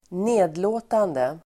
Uttal: [²n'e:dlå:tande]